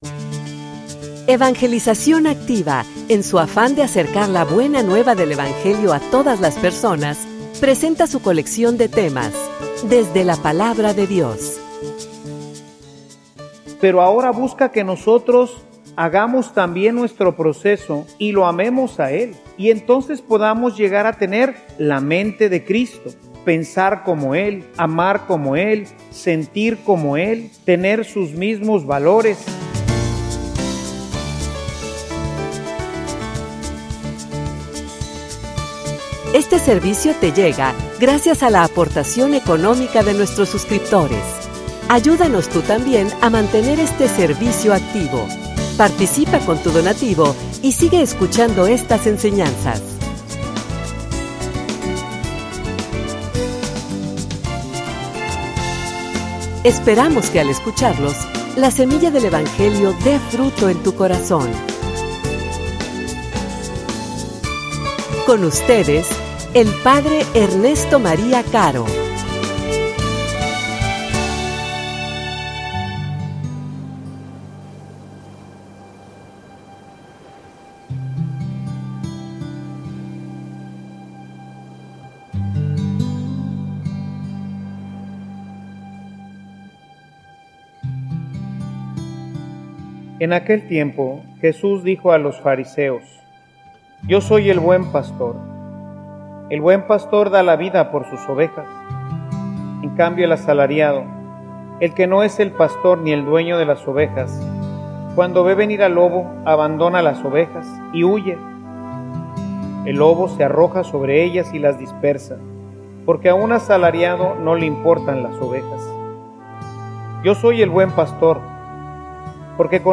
homilia_Conoce_al_Pastor.mp3